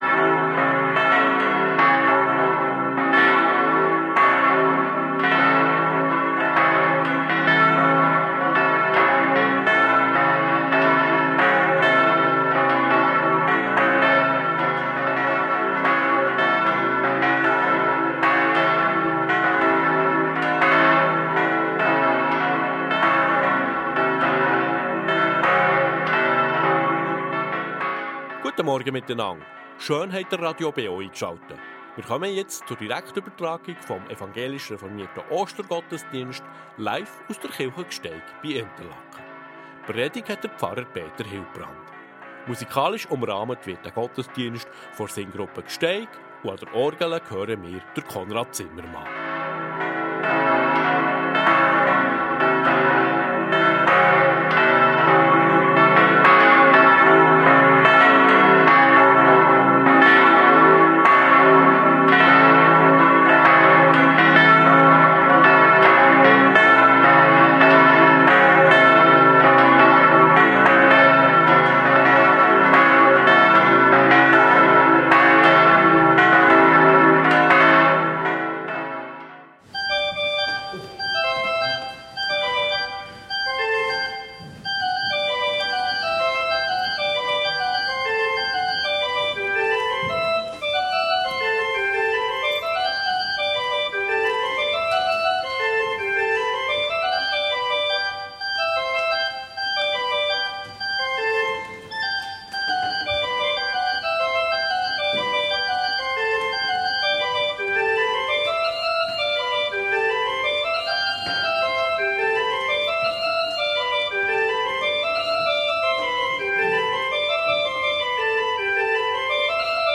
Reformierte Kirche Gsteig bei Interlaken ~ Gottesdienst auf Radio BeO Podcast